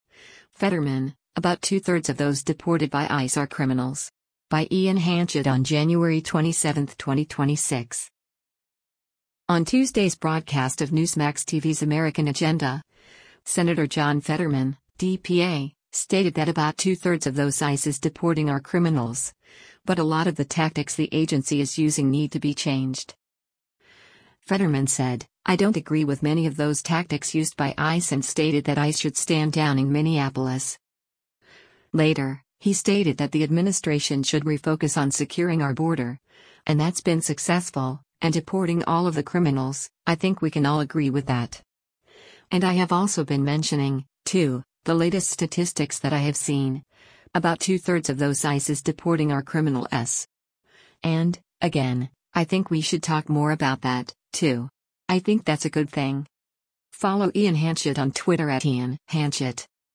On Tuesday’s broadcast of Newsmax TV’s “American Agenda,” Sen. John Fetterman (D-PA) stated that “about two-thirds of those ICE is deporting are” criminals, but a lot of the tactics the agency is using need to be changed.